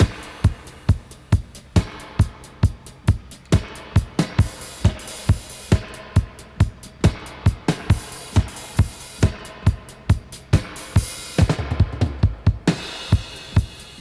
136-DUB-04.wav